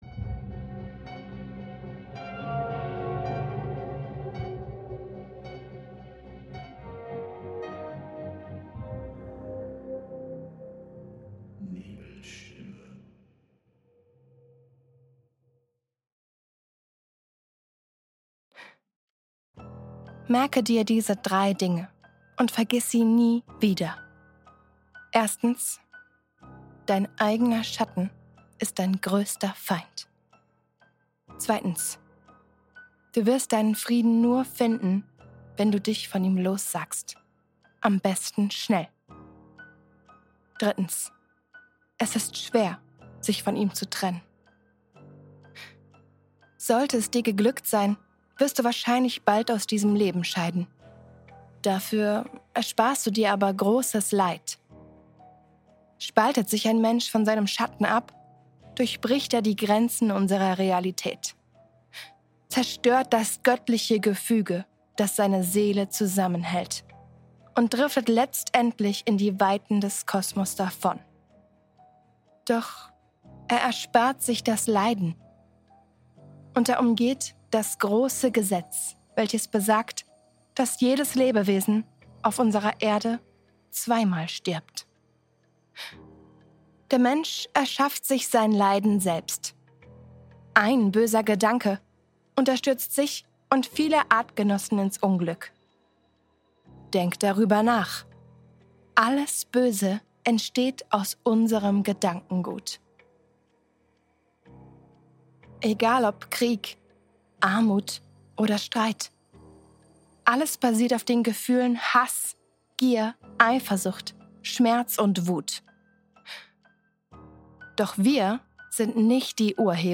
Du hörst hier die neu vertonte Version meiner
früheren Aufnahme – mit dichterer Atmosphäre, klarerem Sound und